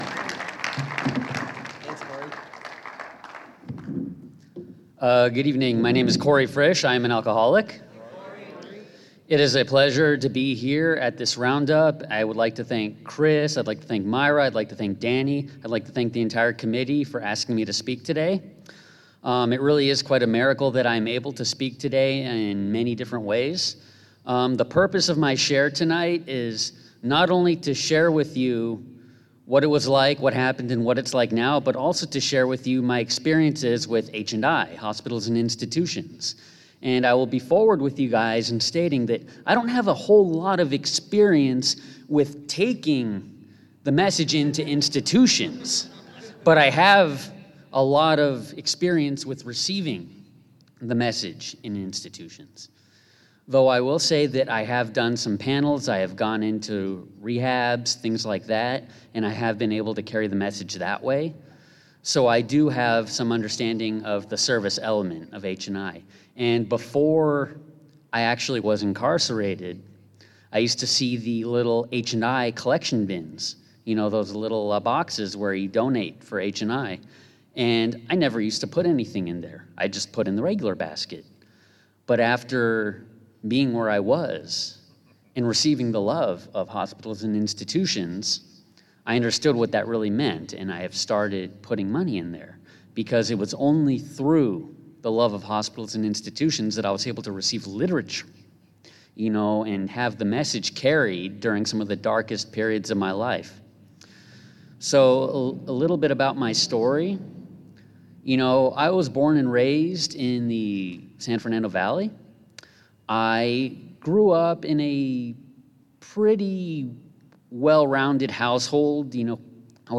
35th Annual Ventura Serenity By The Sea